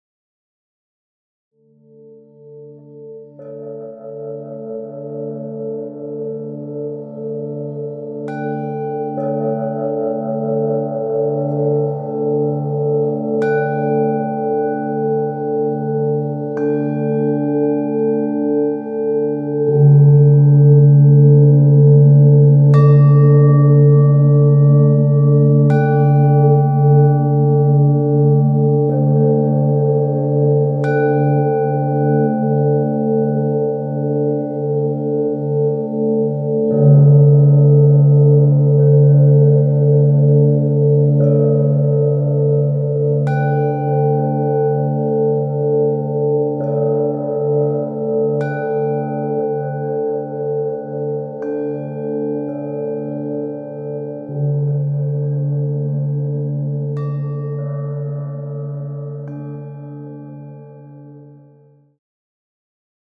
Klangschalen Yoga Musik
u.a. Didgeridoo, Gongs, Klangschalen, Monochord ...